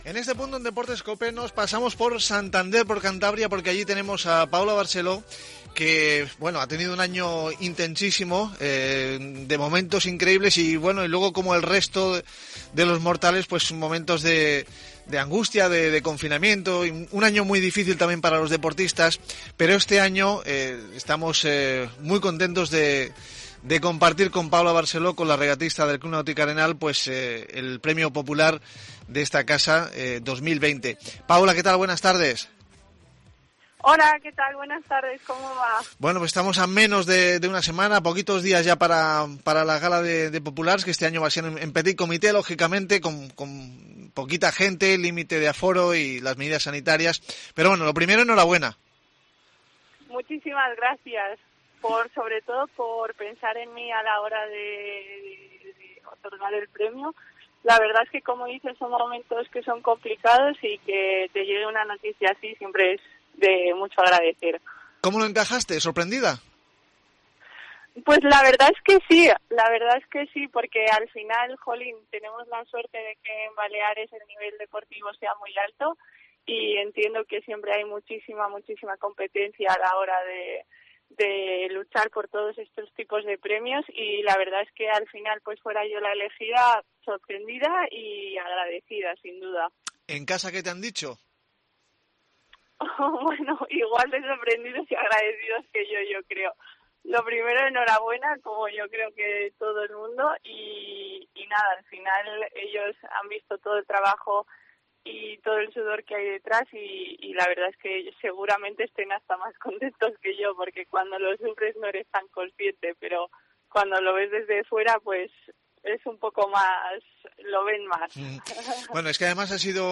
Entrevista a Paula Barceló,